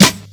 Medicated Snare 1.wav